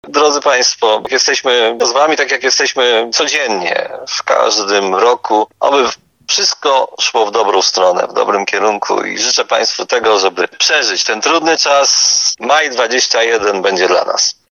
– Pamiętamy o Was – powiedział prezydent Tarnobrzega, Dariusz Bożek.